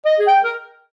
Sound Sets/Clarinet 1